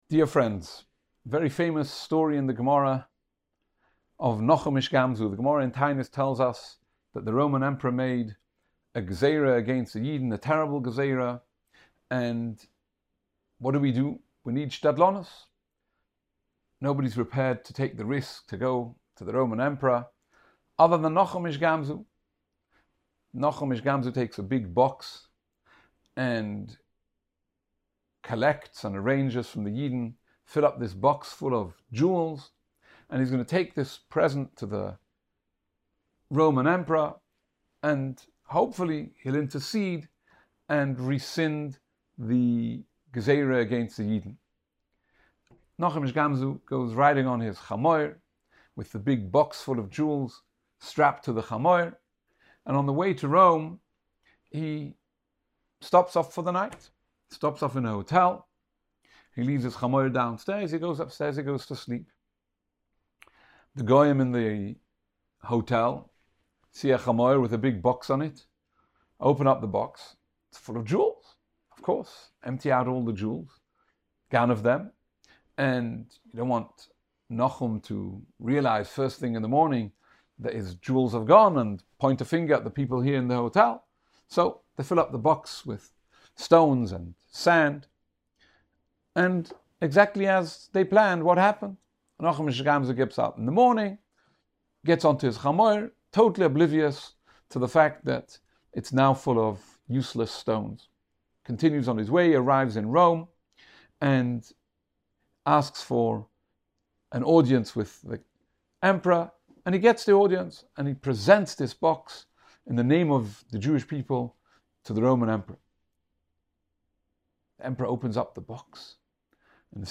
Shiurim